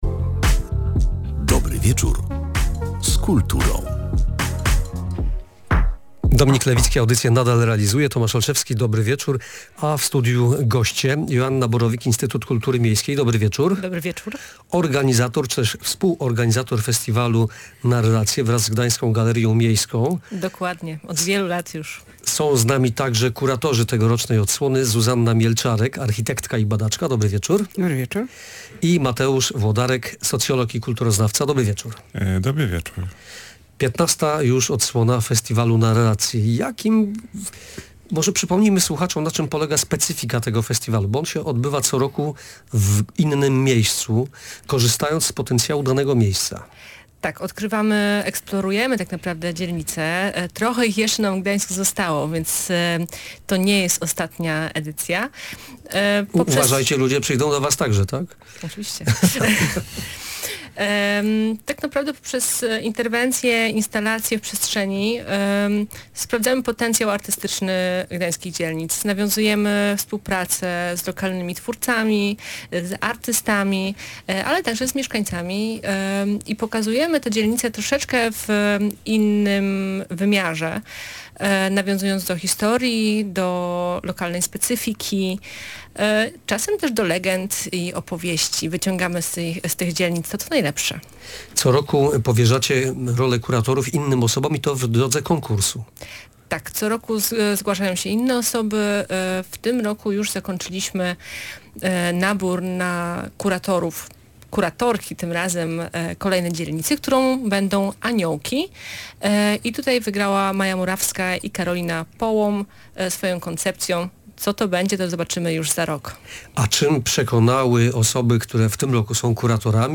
W audycji „Dobry Wieczór z Kulturą”